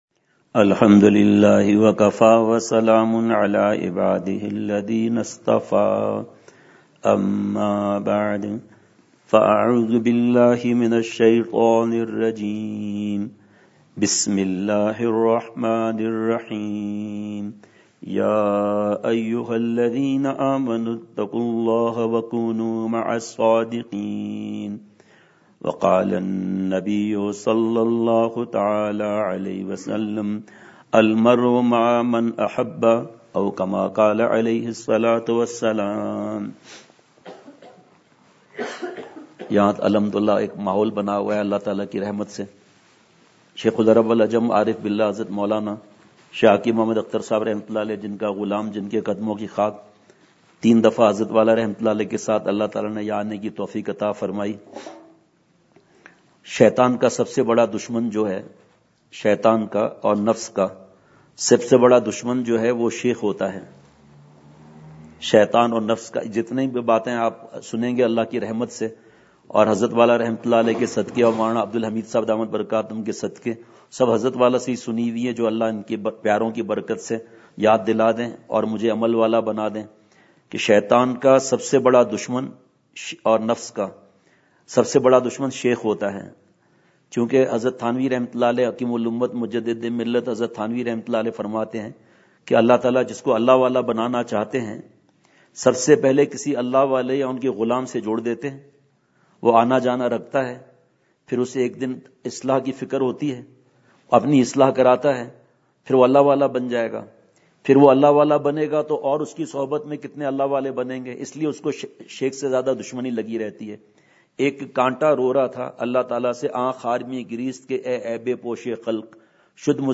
سفر ساؤتھ افریقہ ۱۸ء ,مغرب، ۱۱ دسمبر ۲۰۱۸ء : مسجد دارالعلوم آزاد ول میں درد بھرا بیان !